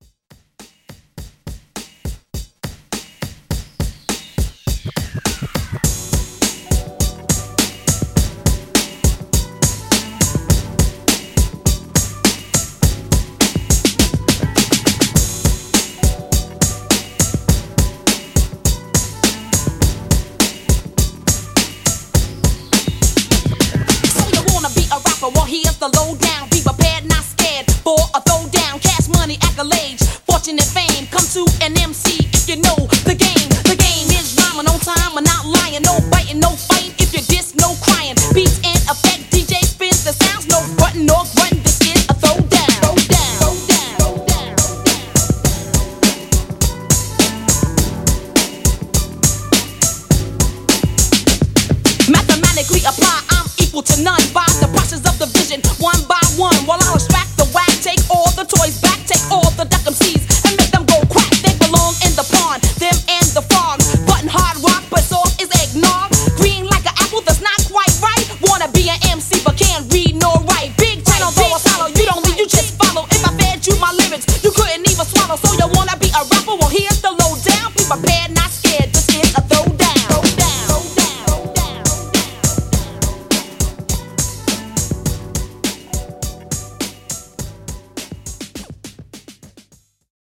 Old School ReDrum)Date Added